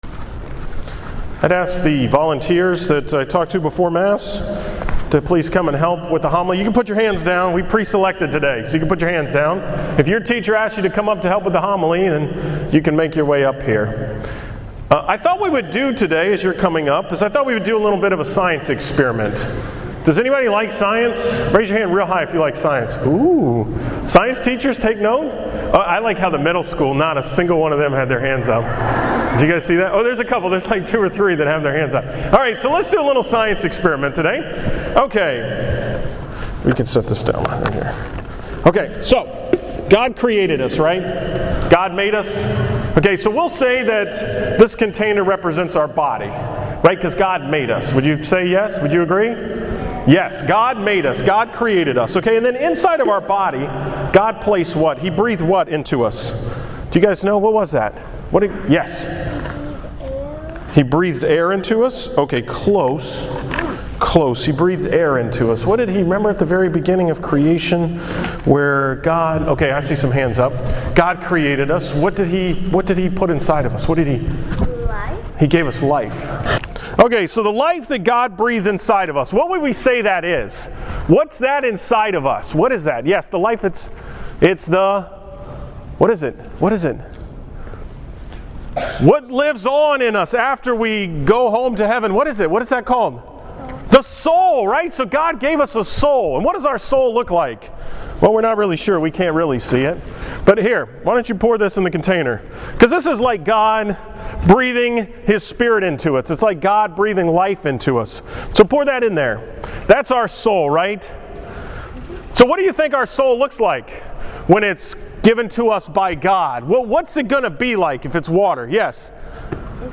From the School Mass on Thursday, October 17, 2013
Category: 2013 Homilies, School Mass homilies